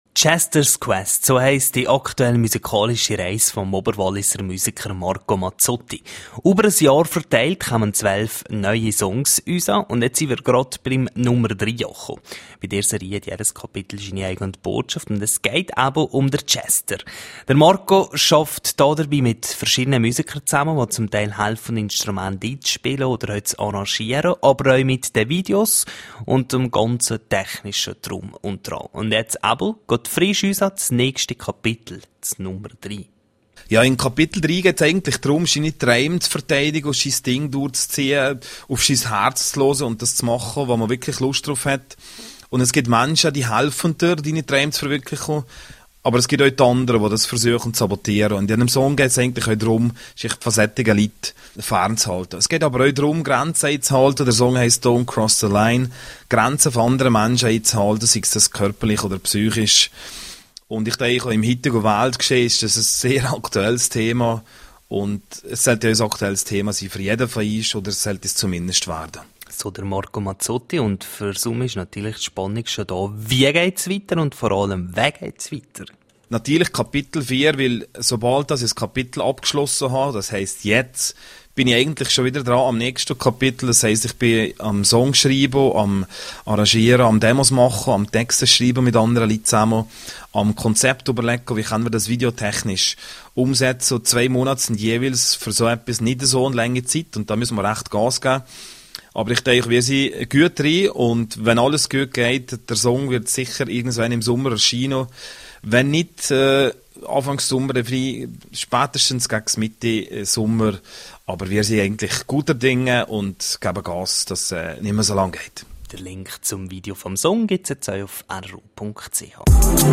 18730_News.mp3